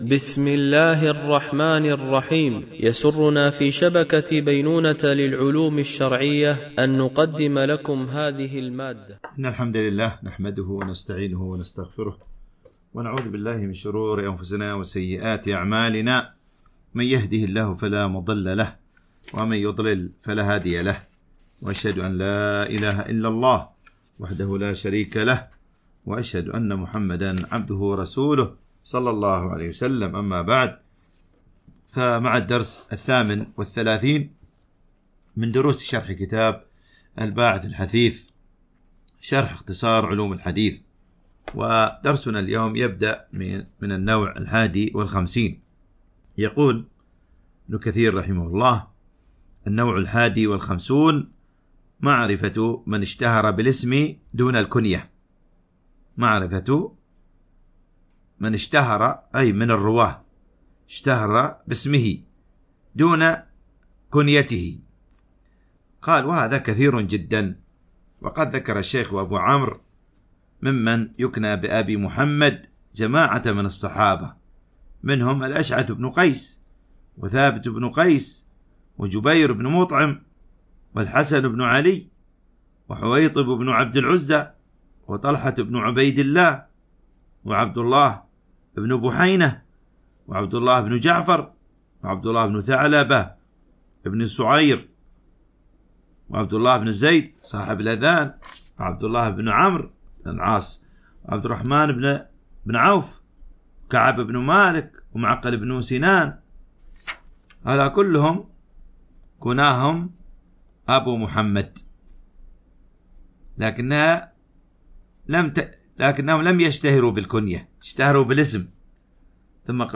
التنسيق: MP3 Mono 22kHz 64Kbps (VBR)